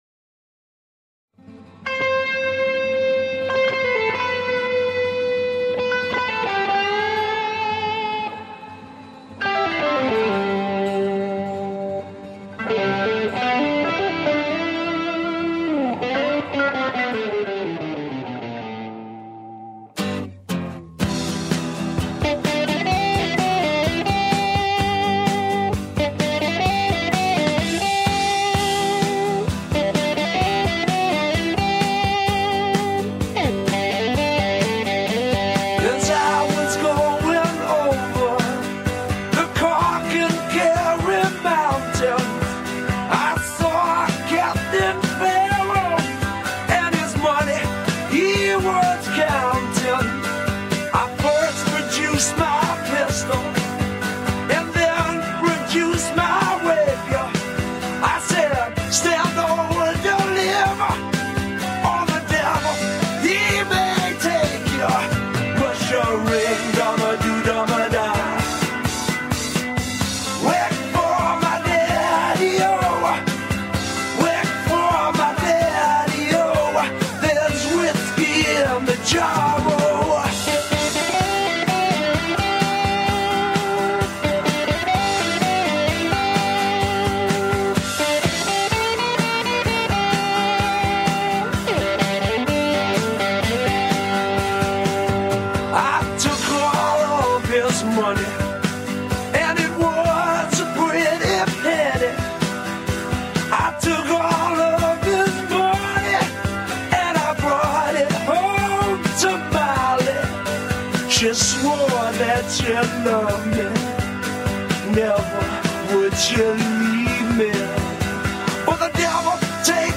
una canción tradicional irlandesa